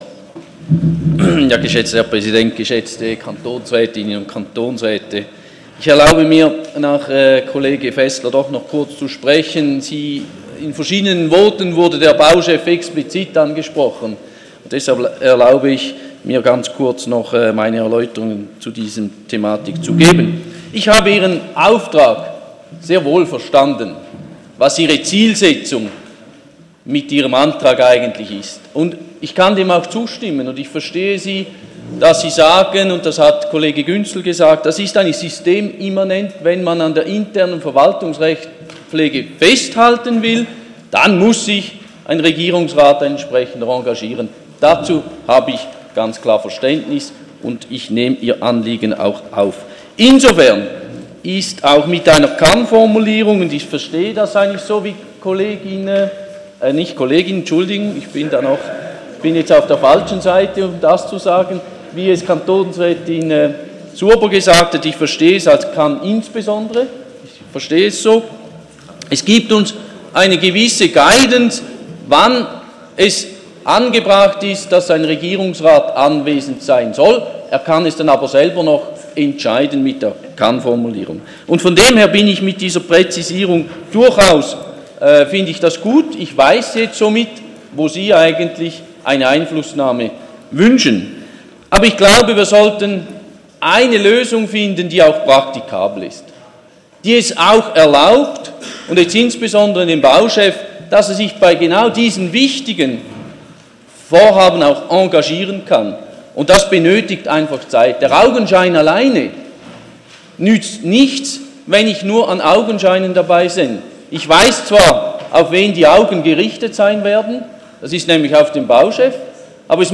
20.9.2016Wortmeldung
Session des Kantonsrates vom 19. und 20. September 2016